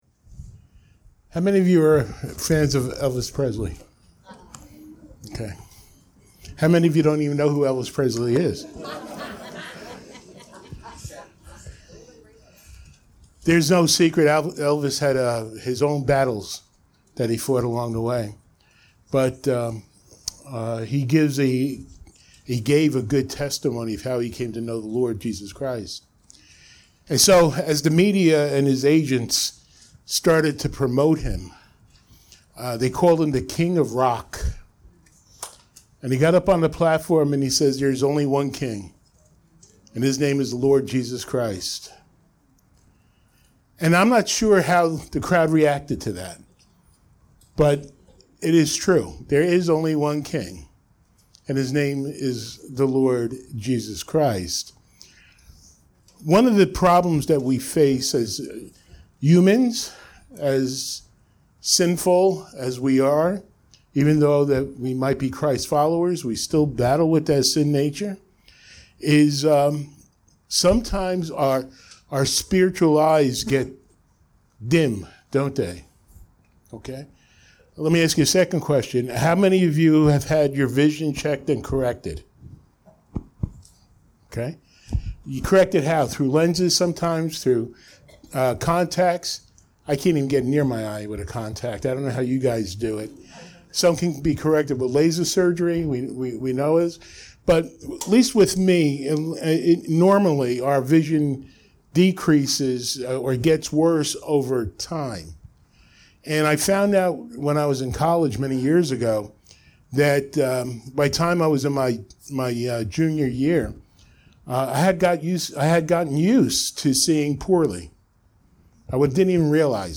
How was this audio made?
Series: Sunday Morning Worship